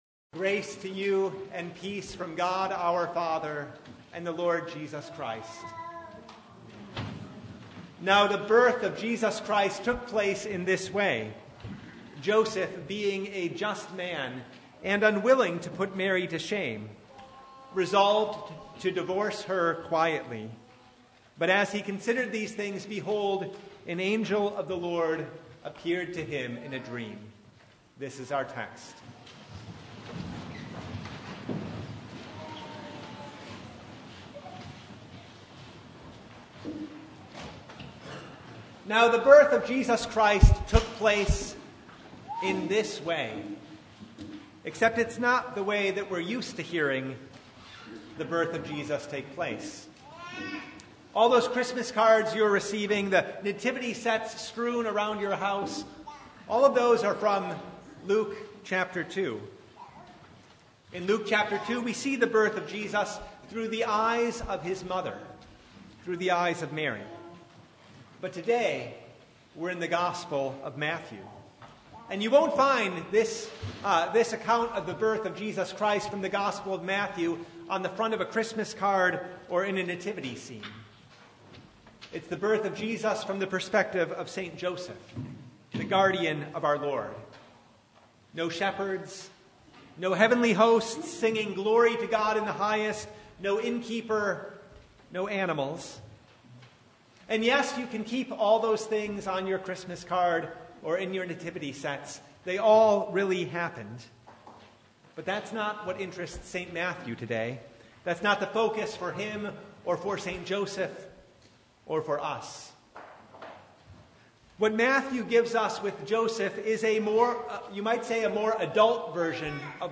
Service Type: Advent
Topics: Sermon Only « Prophesied by Isaiah: The Ransomed Shall Return Ready or Not .